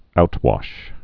(outwŏsh, -wôsh)